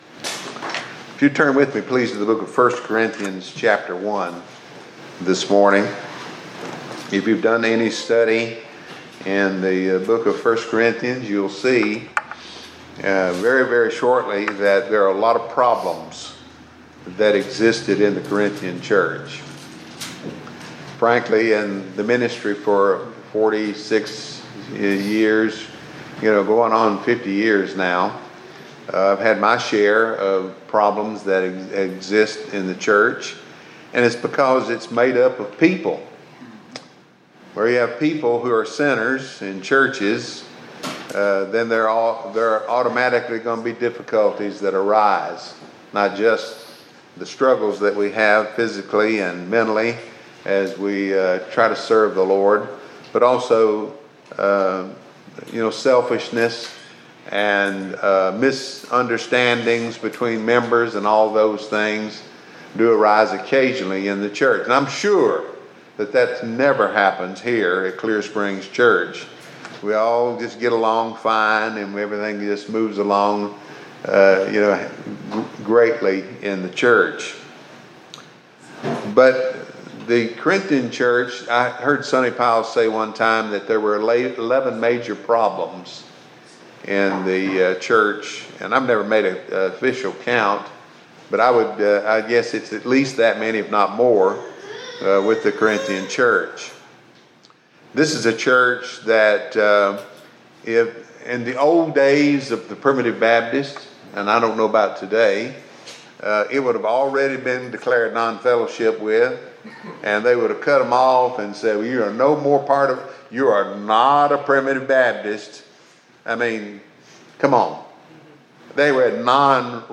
Preached at Gum Log PBC (Bailey, MS) on 3/16/2026.